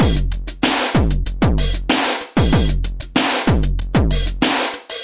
描述：鼓循环，120 bpm，带电子toms的版本。